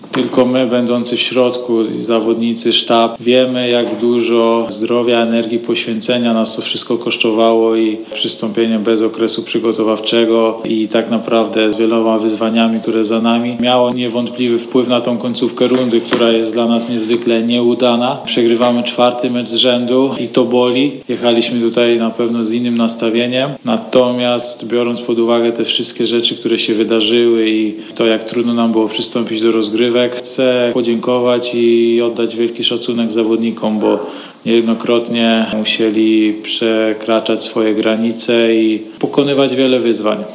na konferencji prasowej powiedział, że nie chciałby, aby ten rezultat wpływał na odbiór tego, co działo się w tej rundzie.